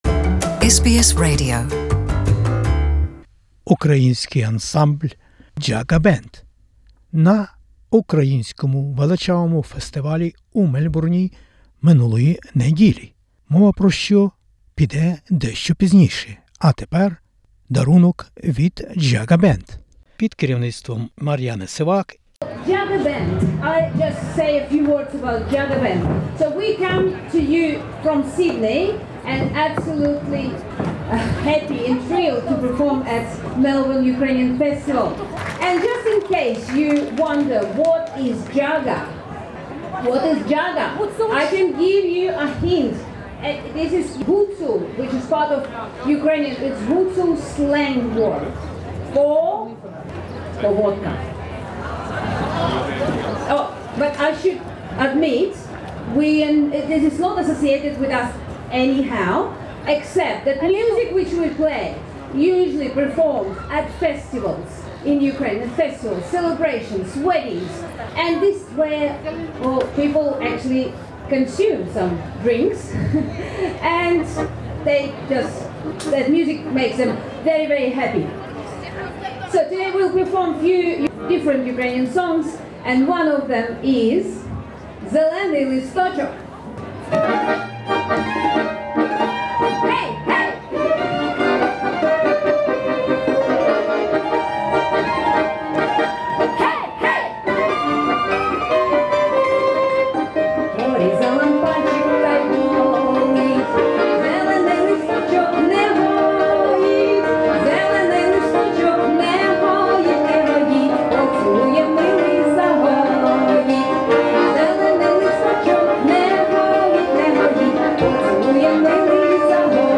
Австралійські українці Вікторії відзначають 70-ліття з часу заснування Української Громади Вікторії. 15 вересня відбувся другий Український фестиваль на Queen Victoria Market.
...A скільки музики, пісень, танців, борщу та вареників було того недільного дня...